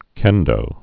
(kĕndō)